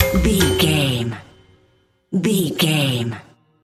Ionian/Major
B♭
drums
percussion
double bass
silly
circus
goofy
comical
cheerful
perky
Light hearted
quirky